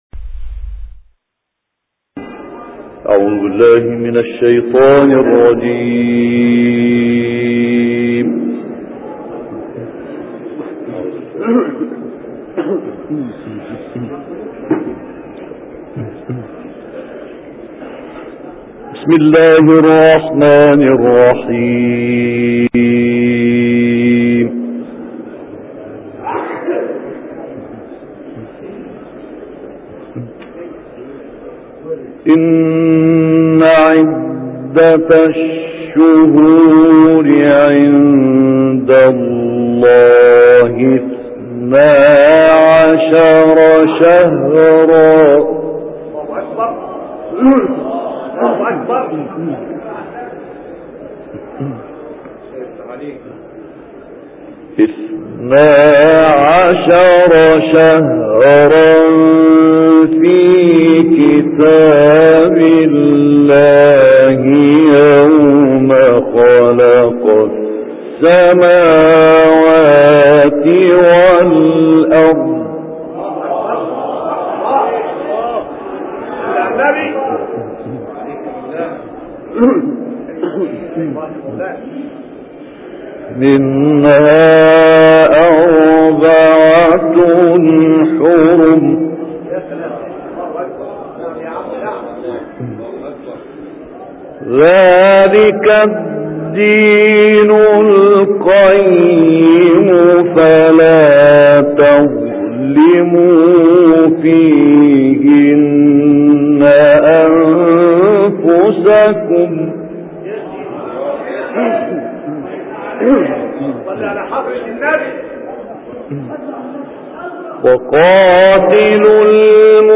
تلاوت مجلسی «احمد عامر» از سوره توبه
گروه فعالیت‌های قرآنی: تلاوت مجلسی آیاتی از سوره توبه با صوت مرحوم استاد احمد عامر را می‌شنوید.